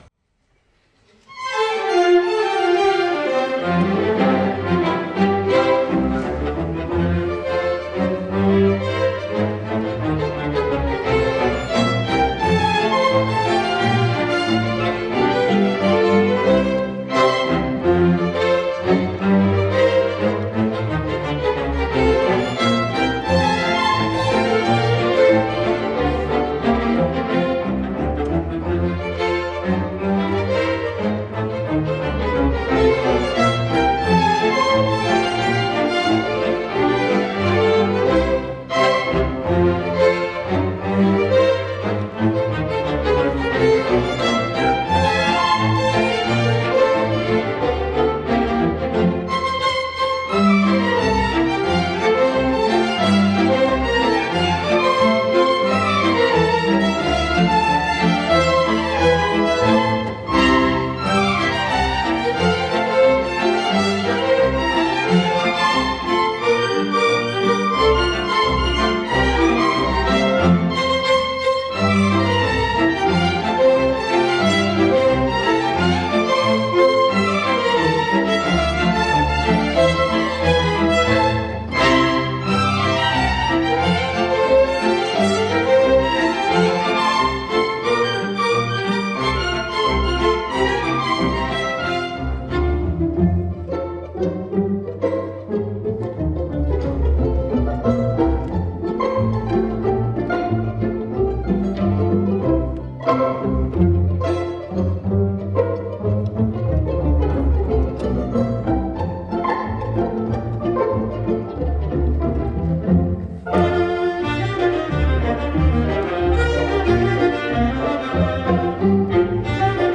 In its heyday from 1895 to 1918, the syncopated rhythms and sophisticated melodies of ragtime music conquered the world and influenced many "serious" composers, including Erik Satie, Claude Debussy and Igor Stravinsky, Here's an example of Scott Joplin's unique ability to combine toe-tapping rhythms with elegant harmonies.